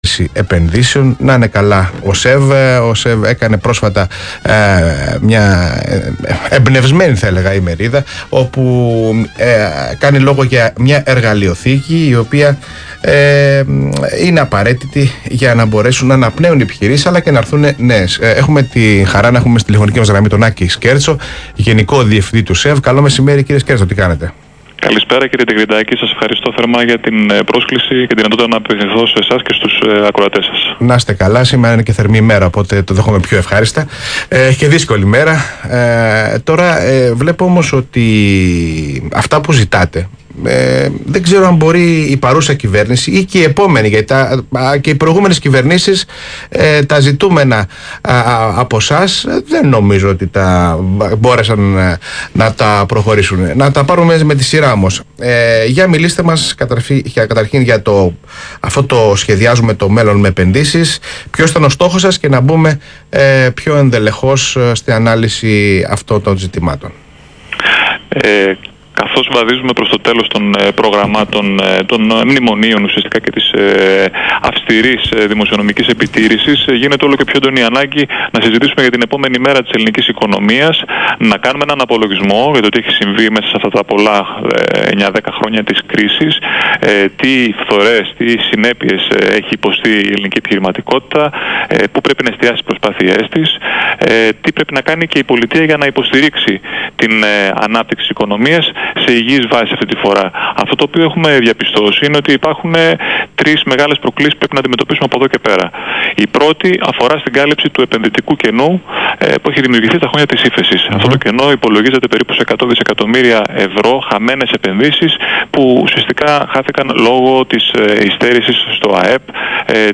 Συνέντευξη του Γενικού Διευθυντή του ΣΕΒ, κ. Άκη Σκέρτσου στον Ρ/Σ ALPHA RADIO, 27/4/2018